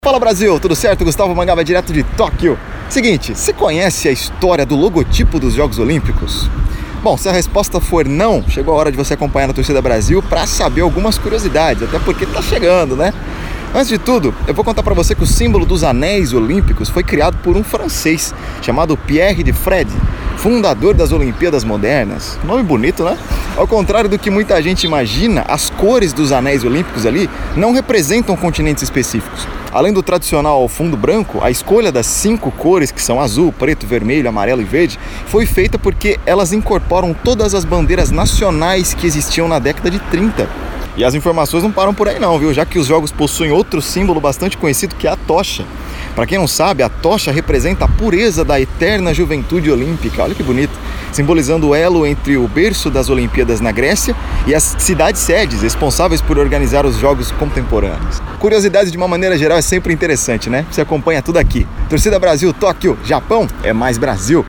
TORCIDA BRASIL – Boletim direto de Tóquio